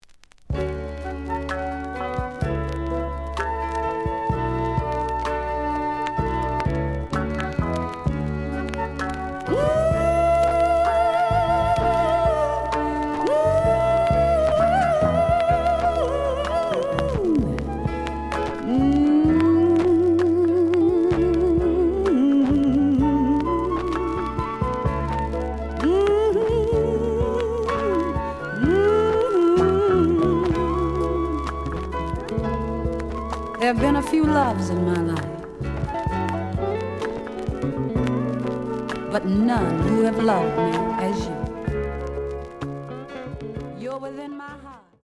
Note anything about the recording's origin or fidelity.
The audio sample is recorded from the actual item. Some noise on B side.